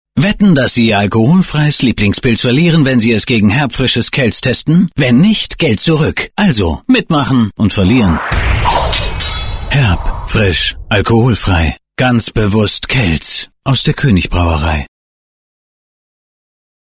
Kelts Funkspot